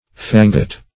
fangot - definition of fangot - synonyms, pronunciation, spelling from Free Dictionary
Search Result for " fangot" : The Collaborative International Dictionary of English v.0.48: Fangot \Fan"got\, n. [Cf. It. fagotto, fangotto, a bundle.